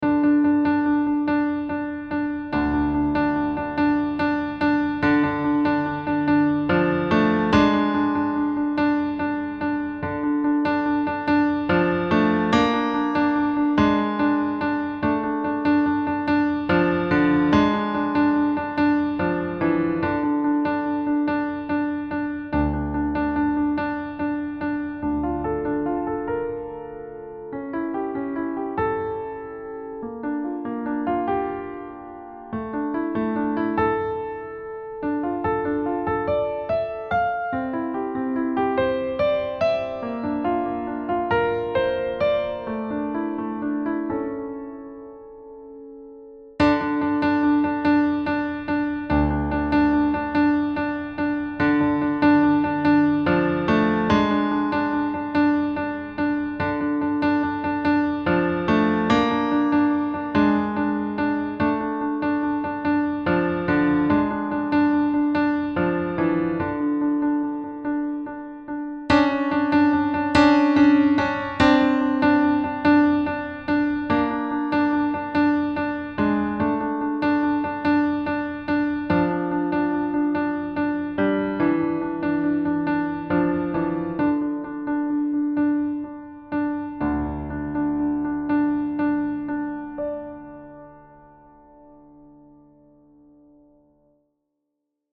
Key: D minor with accidentals
Time Signature: 4/4 (BPM ≈ mid-to-fast)